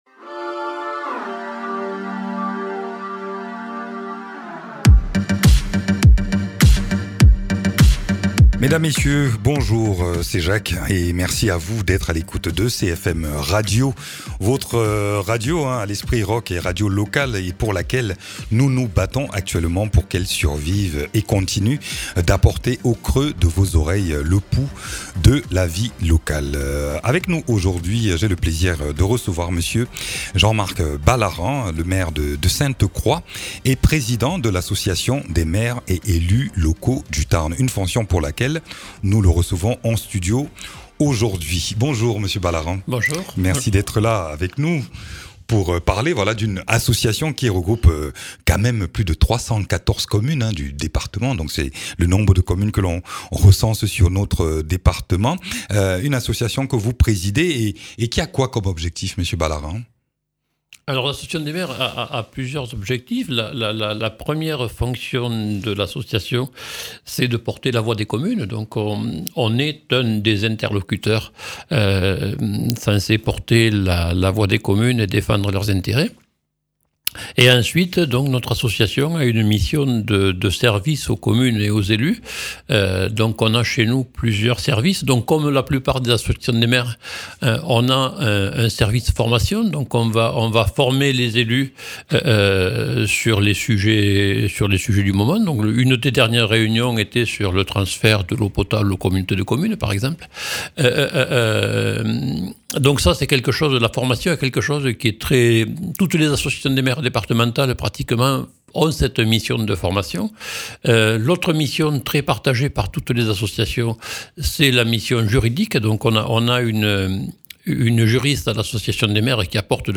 Interviews
Invité(s) : Jean-Marc Balaran, Président de l’association des maires du Tarn.